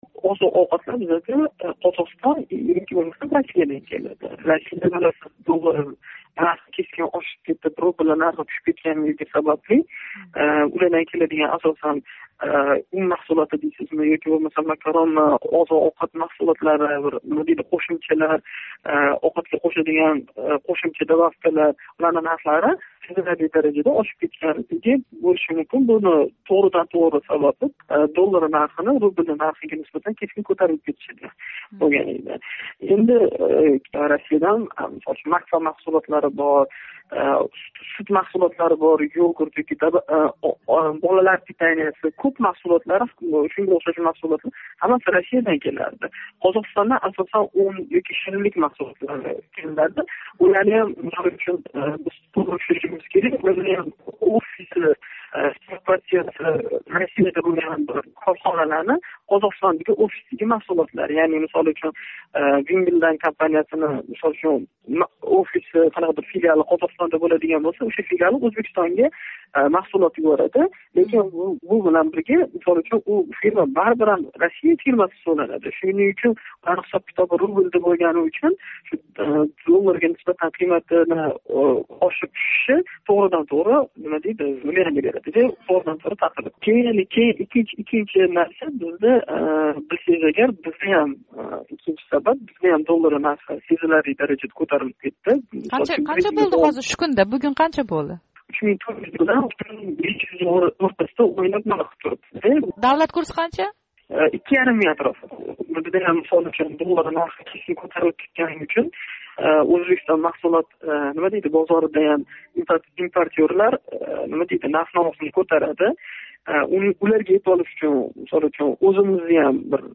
Иқтисодчи: Ўзбекистонда нархларнинг ошиши кутилган эди